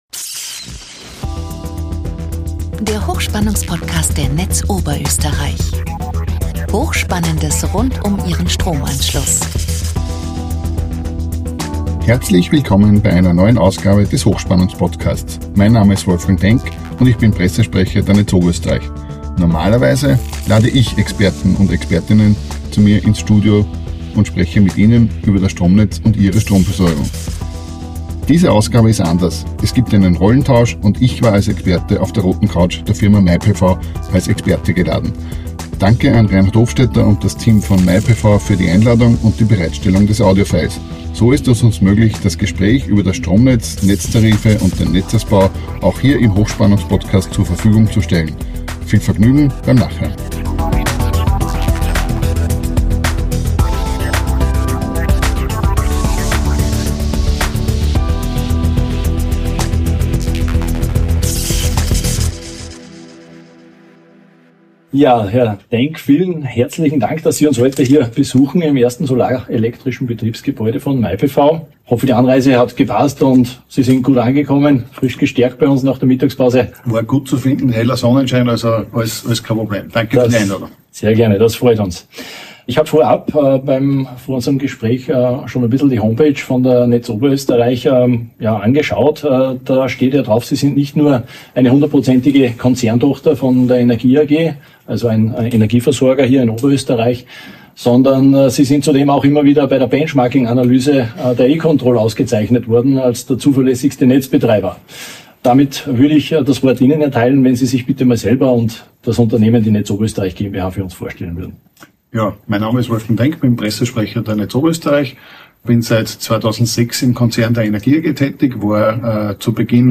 Expertengespräch auf der roten Couch von my-PV ~ Der HochspannungsPodcast Podcast